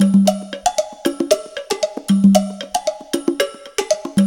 CONGA BEAT26.wav